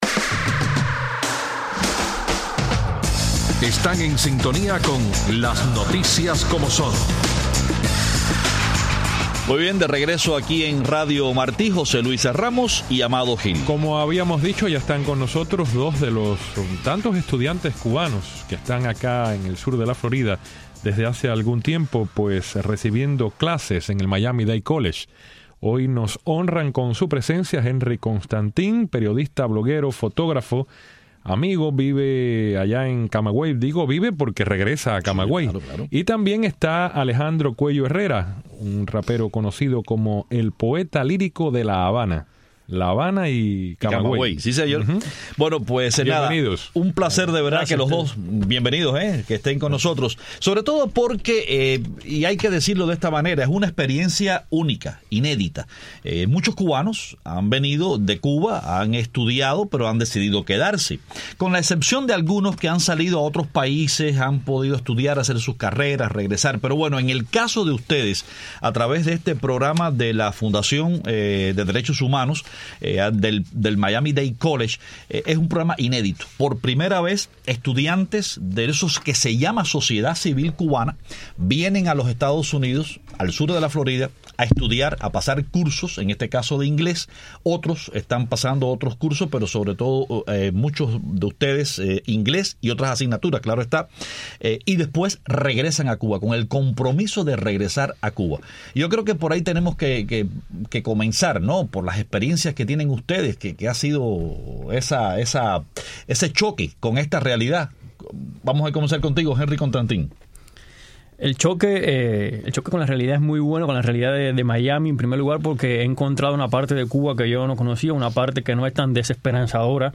Hoy conversamos con dos jóvenes de la sociedad civil en Cuba que están estudiando en el Miami Dade College a través de un programa de intercambio auspiciado por la Fundación Nacional Cubana Americana. Al terminar sus estudios en julio, regresan a Cuba.